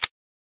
grenadepull.ogg